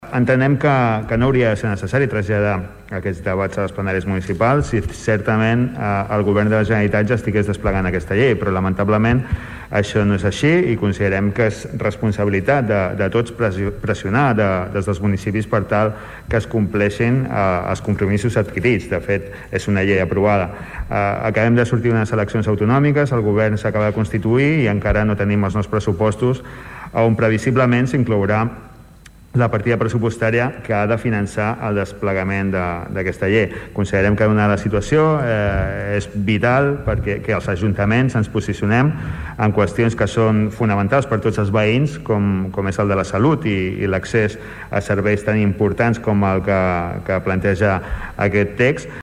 En Comú Podem Tordera va presentar ahir en el plenari una moció per la implantació de la salut bucodental integral en l’àmbit de la sanitat pública.
Des del grup municipal d’ En Comú Podem creuen que la salut de les persones ha d’anar per sobre dels interessos econòmics d’uns pocs i és per això que proposa a la moció que el pressupost de sanitat 2021 es dediqui una partida per a reglamentar i desplegar la llei 12/2020, del 13 d’octubre de salut bucodental. Defensava la moció el regidor d’en Comú Podem, Salvador Giralt.